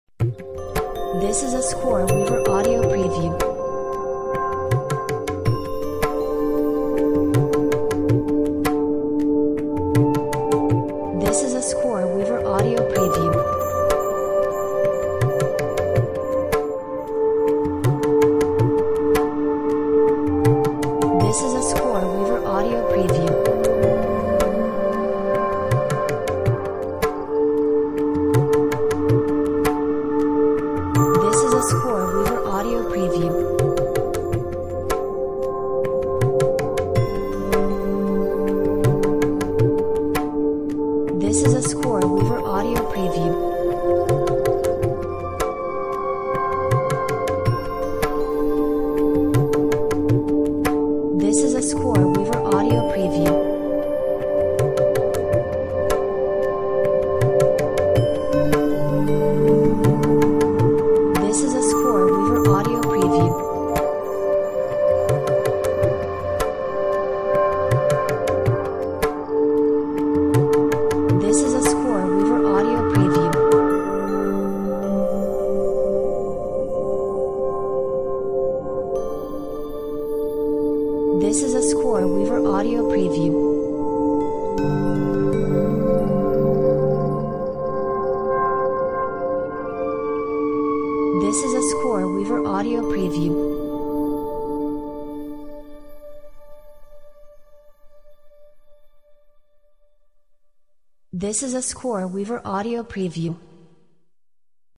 Relaxing Meditation Music with a hint of India.